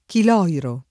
[ kil 0 iro ]